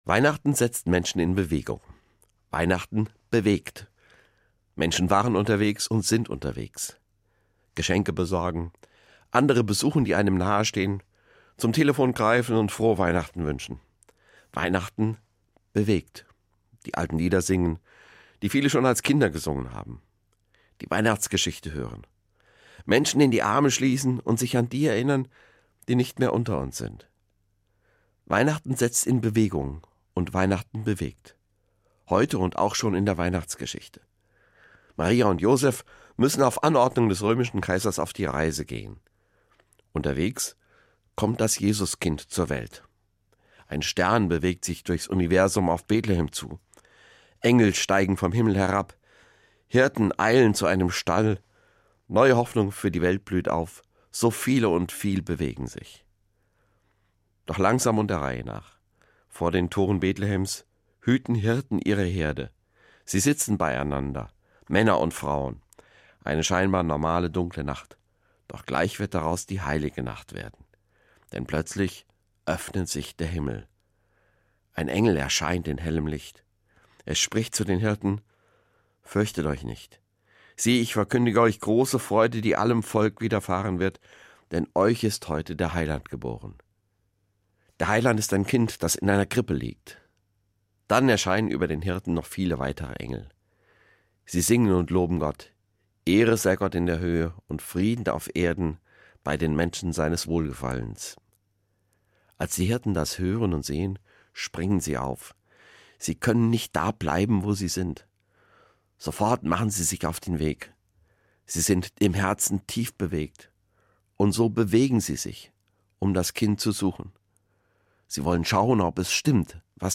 Eine Sendung von Dr. Dr. h.c. Volker Jung, Kirchenpräsident der Evangelischen Kirche in Hessen und Nassau, Darmstadt